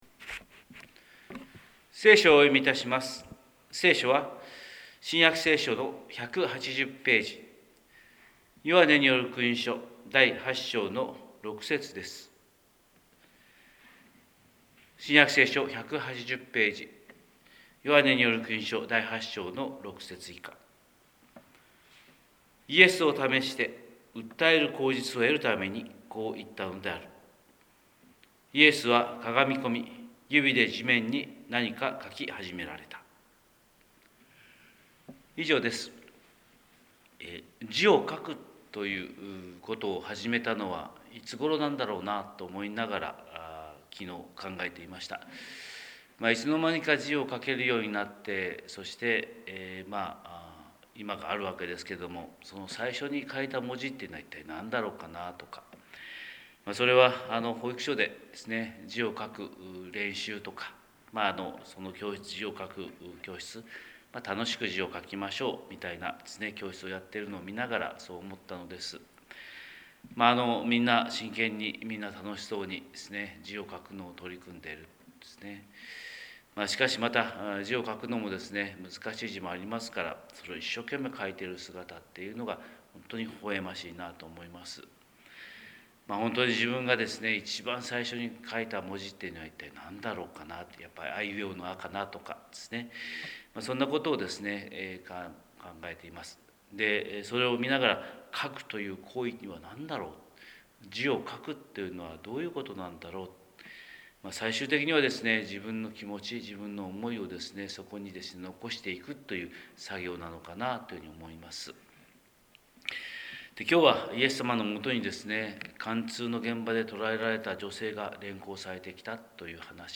神様の色鉛筆（音声説教）: 広島教会朝礼拝250228